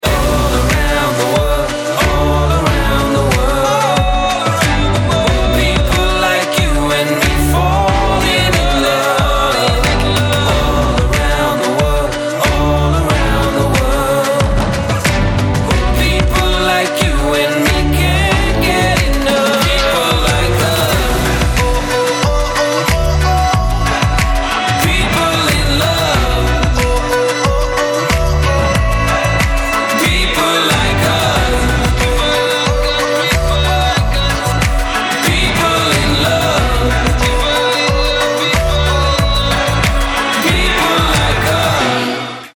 • Качество: 128, Stereo
поп
красивые
dance
Electronic
красивый мужской вокал